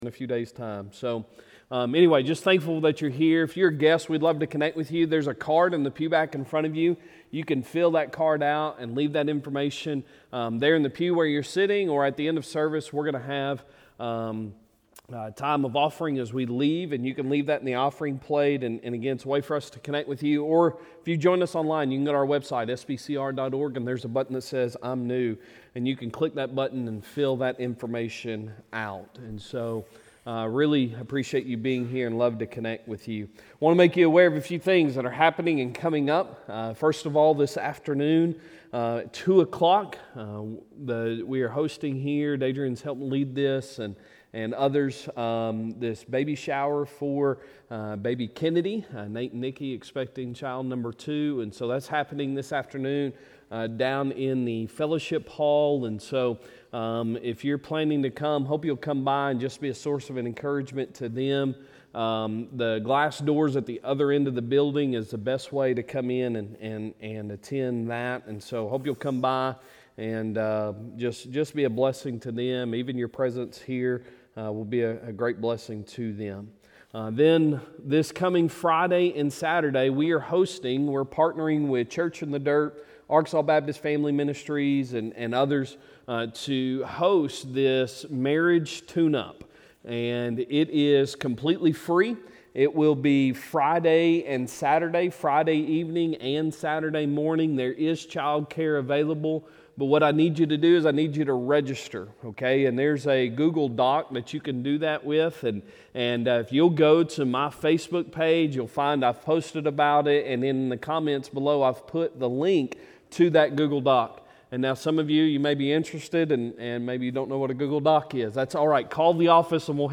Sunday Morning Sermon April 6, 2025